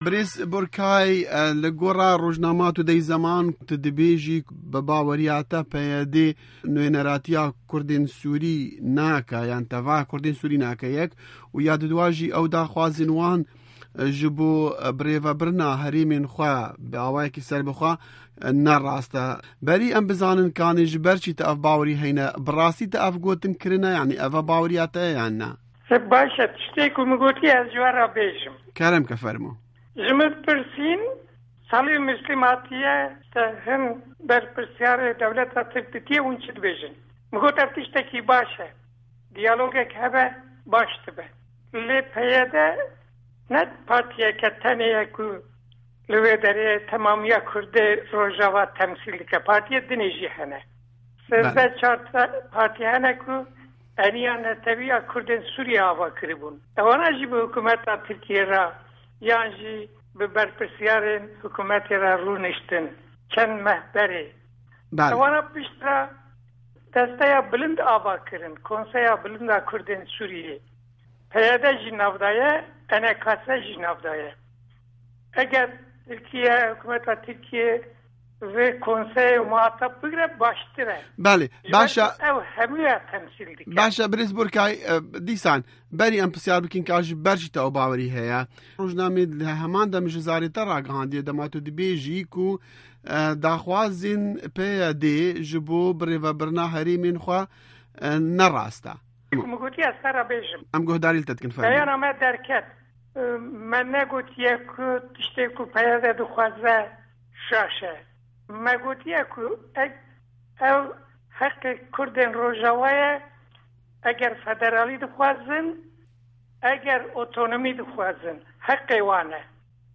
Hevpeyvîn digel Kemal Burkayî